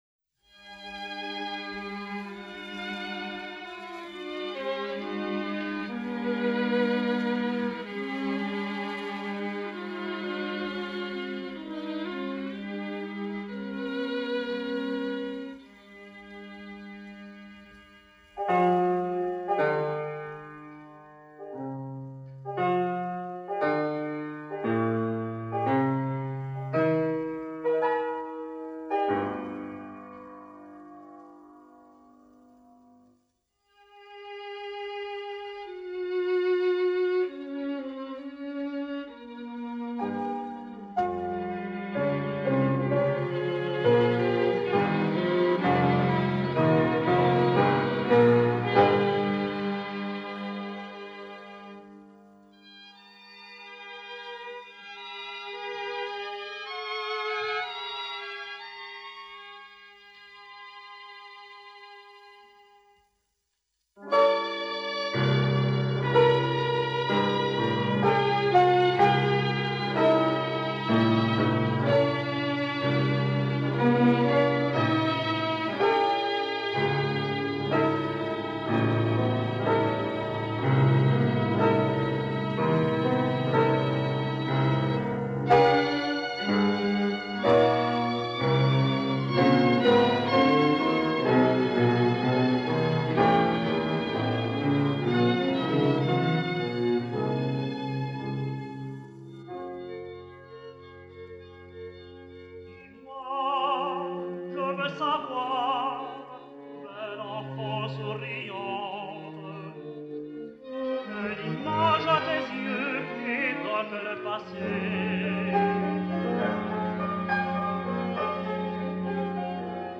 (modérément animé)